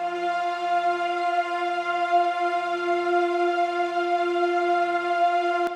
piano-sounds-dev
f6.wav